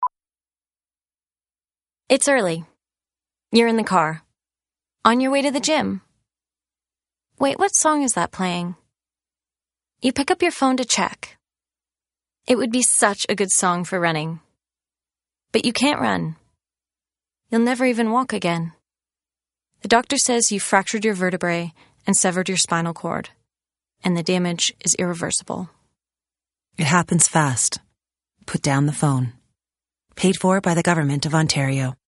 BronzePublic Service - Radio Single
It Happens Fast Radio - Gym